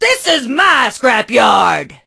pam_lead_vo_02.ogg